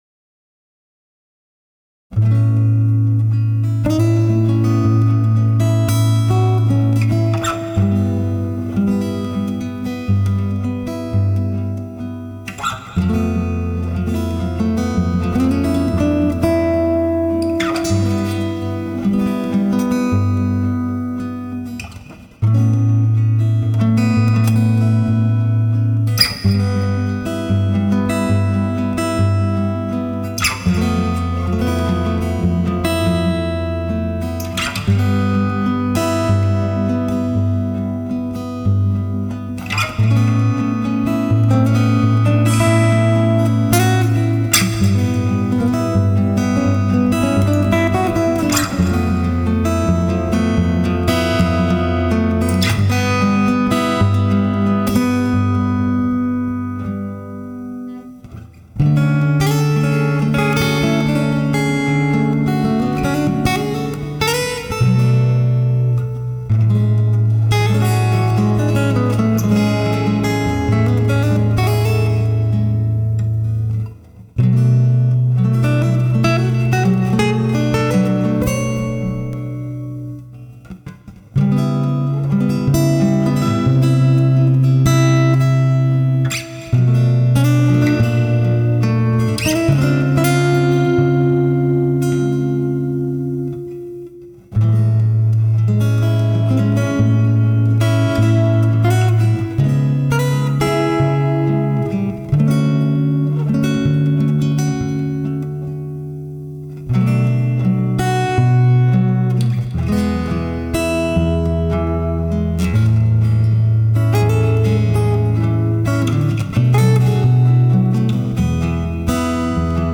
5.32MBytes, WMA file   This instrumental tune was submited to the exhibition "Cherry blossom viewing"in gellery "PARAISO" as music cd.